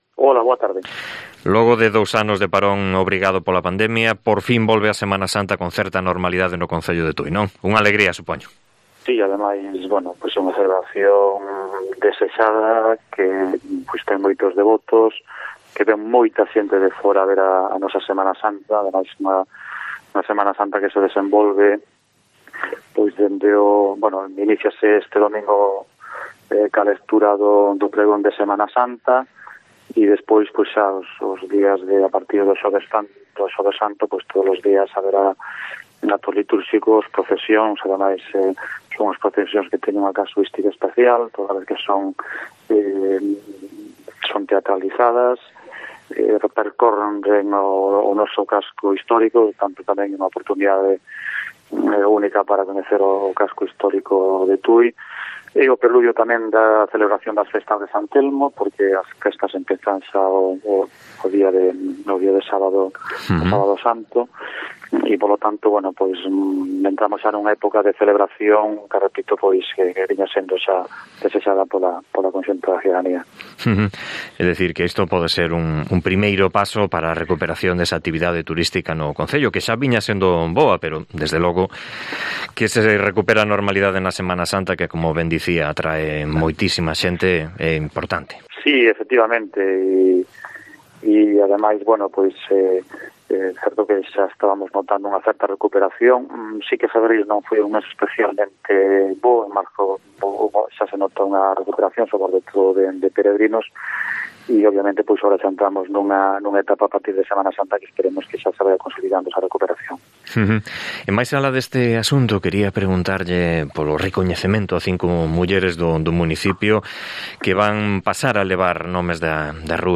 AUDIO: El alcalde, Enrique Cabaleiro, espera que la Semana Santa sea un revulsivo más para el turismo de la villa tudense